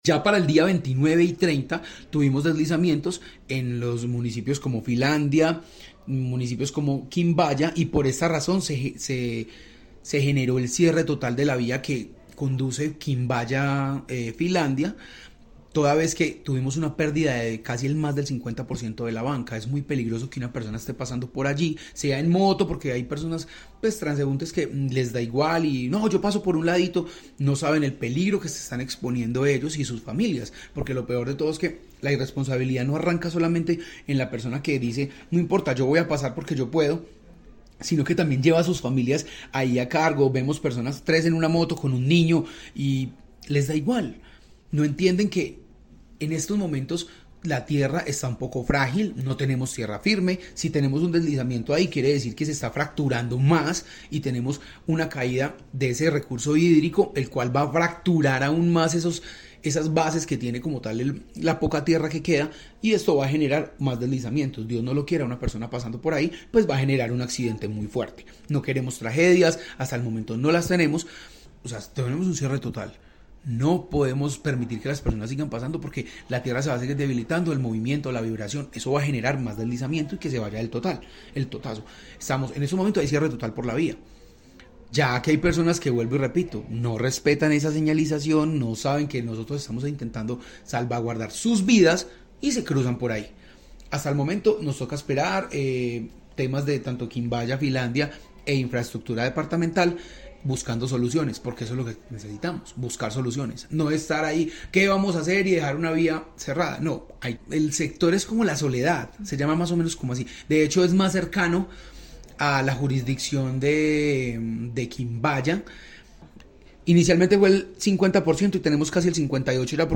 Director de la Udegerd